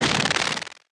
tesla-turret-beam-deflection-2.ogg